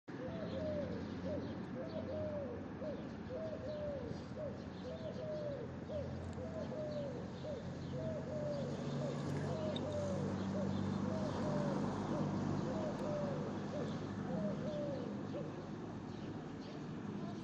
Collared Dove, Streptopelia decaocto
StatusSinging male in breeding season